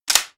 snd_pickupitem.ogg